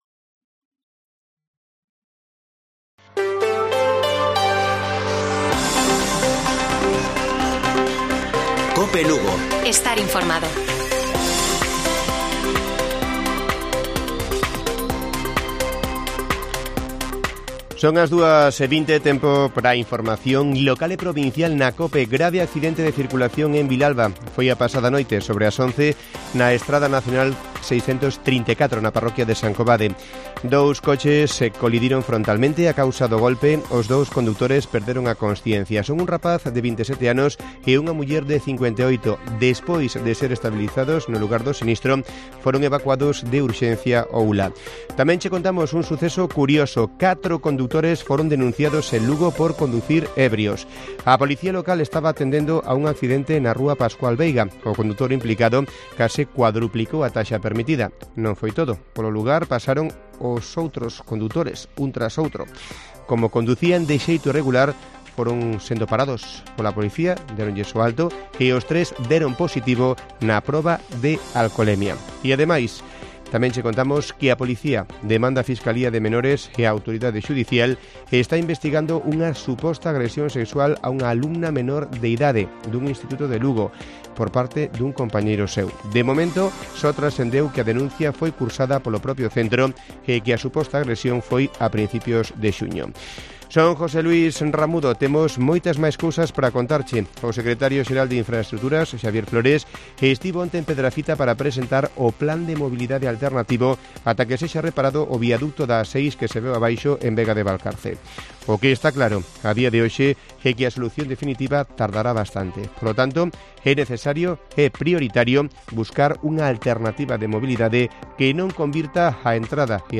Informativo Mediodía de Cope Lugo. 01 DE JULIO. 14:20 horas